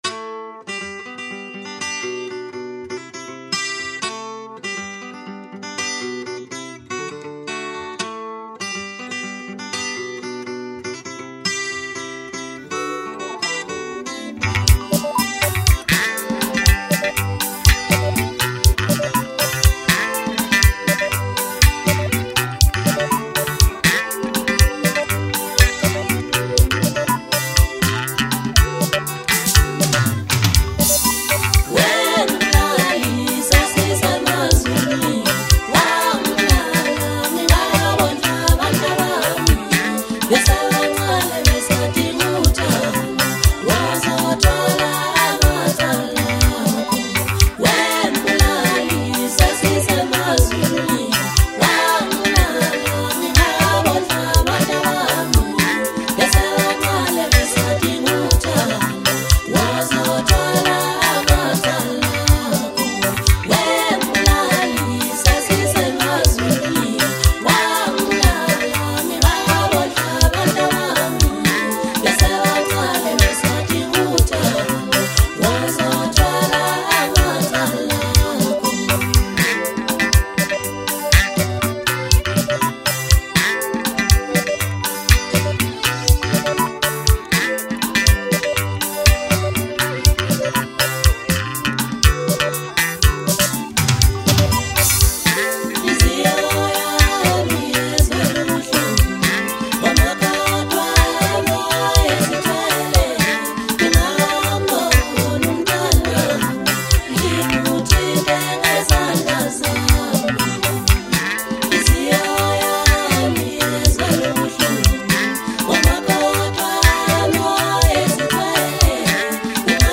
Home » Maskandi » DJ Mix » Hip Hop
South African singer-songwriter